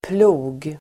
Ladda ner uttalet
Uttal: [plo:g]